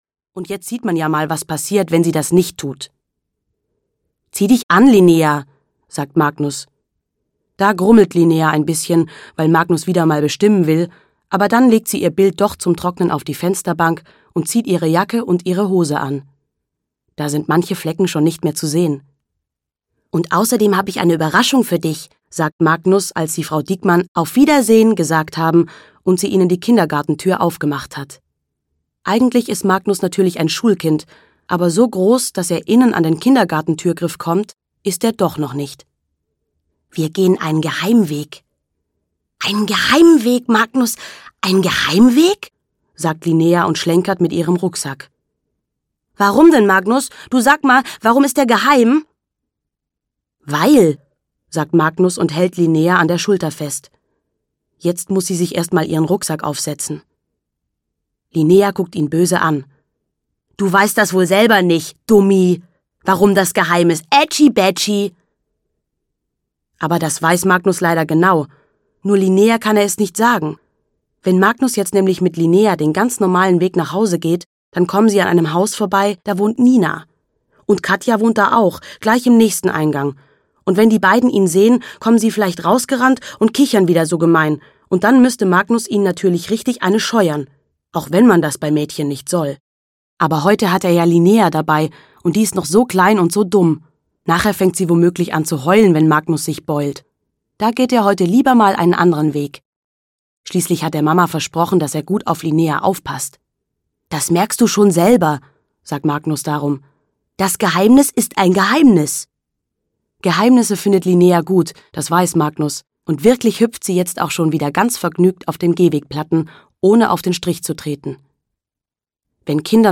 Linnea rettet schwarzer Wuschel - Kirsten Boie - Hörbuch